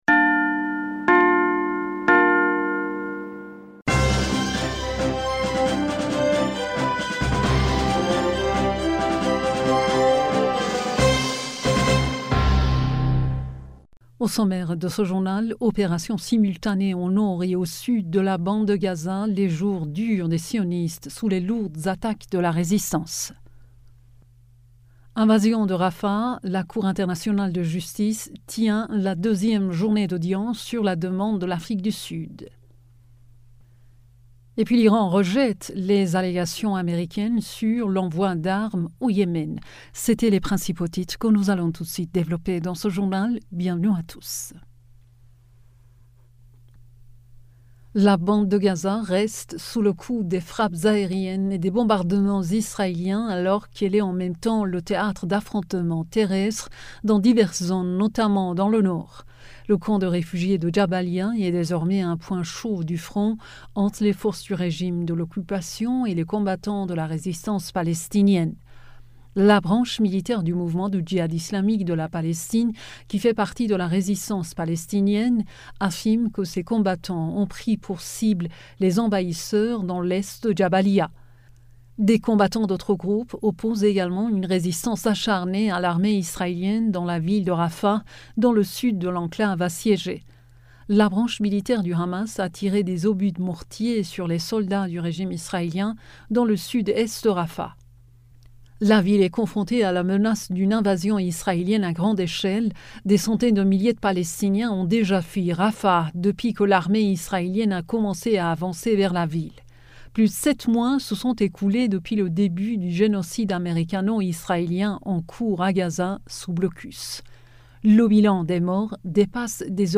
Bulletin d'information du 17 Mai